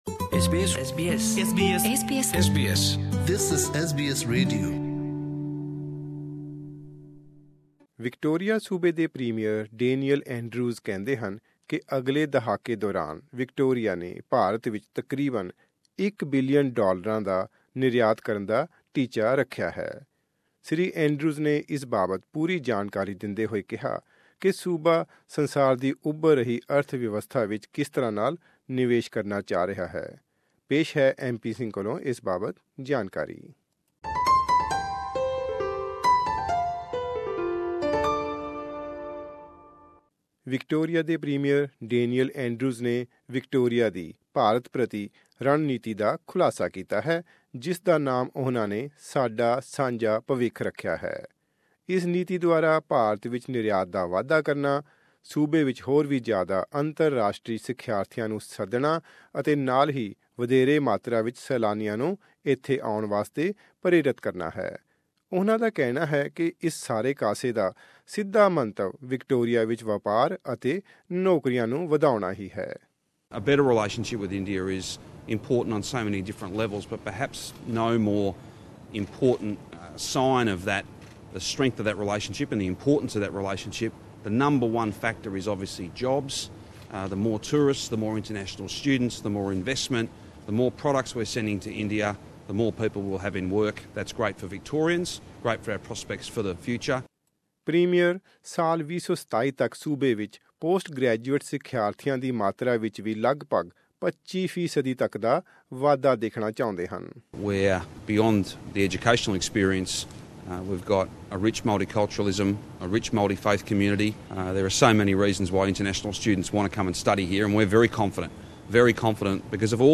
Victorian Premier Daniel Andrews speaks to the media. Source: SBS